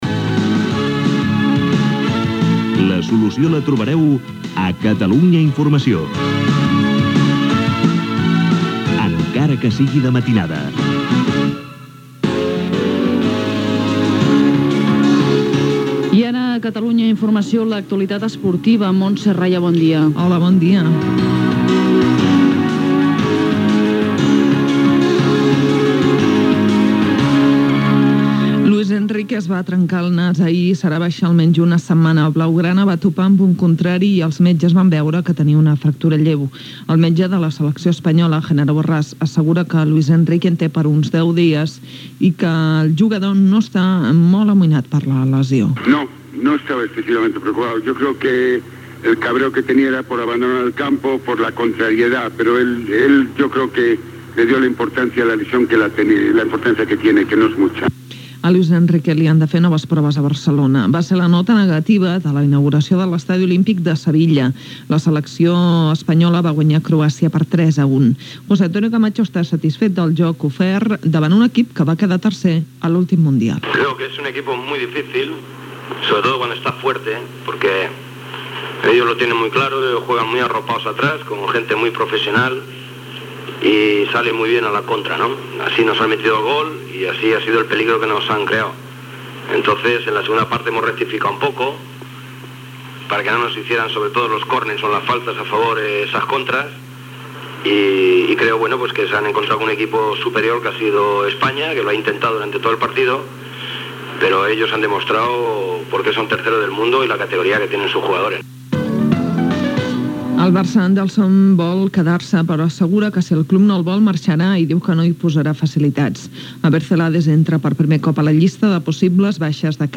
etc. Identificació de l'emissora.
Informatiu
FM